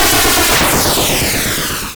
WashingMachine.wav